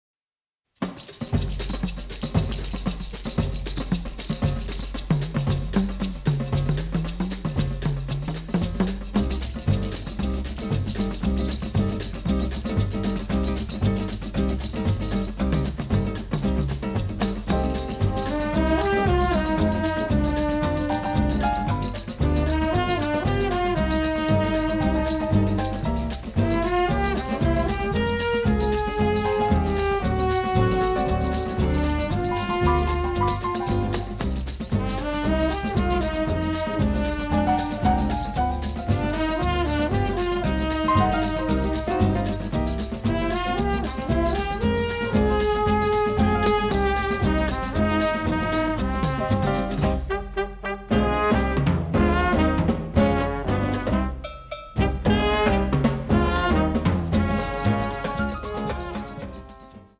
Chorus 1-20